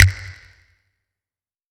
TC3Snare23.wav